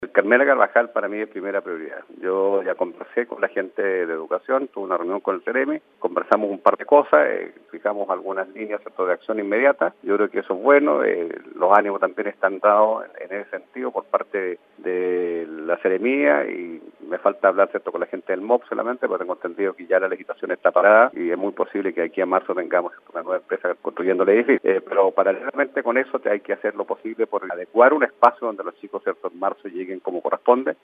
liceo-carvajal-prioridad-alcalde-osorno.mp3